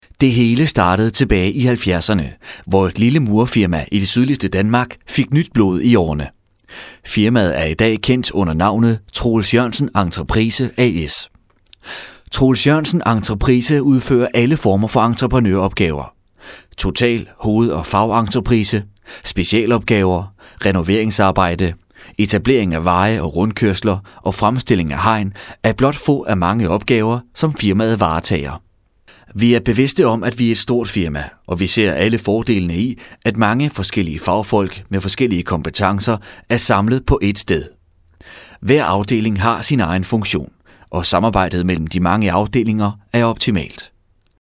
Sprecher dänisch
Sprechprobe: Werbung (Muttersprache):
voice over artist danish